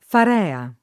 farea [ far $ a ]